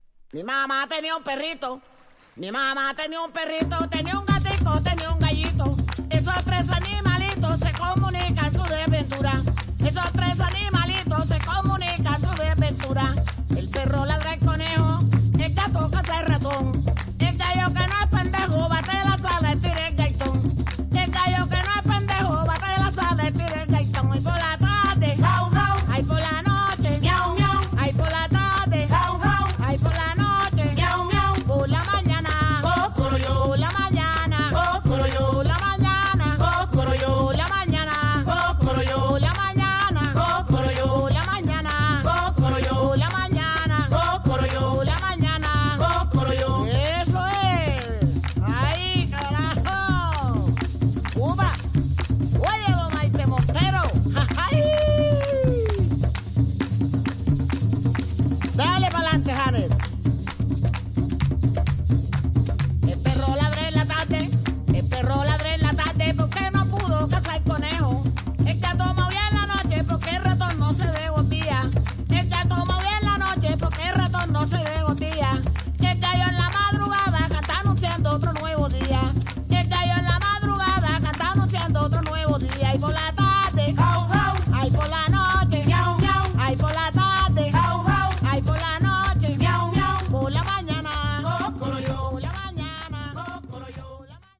Tags: Folklorico , Colombia